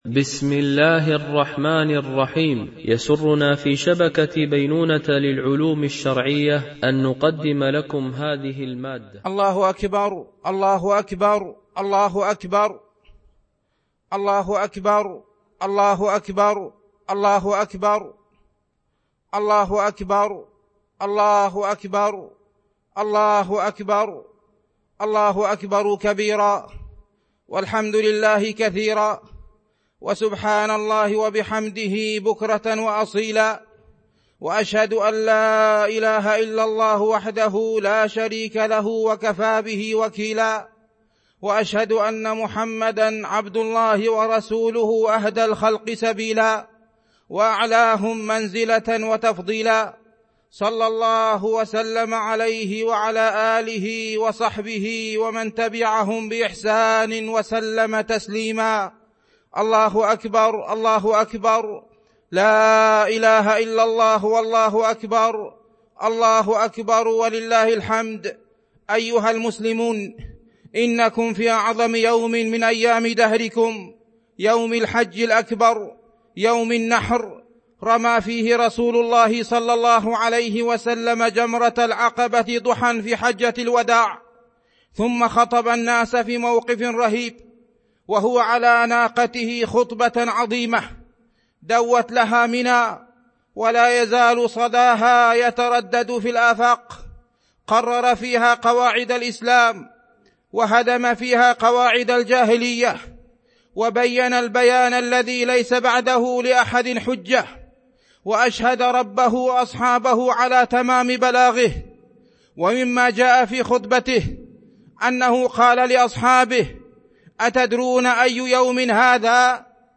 خطبة عيد الأضحى ١٤٤٢ هـ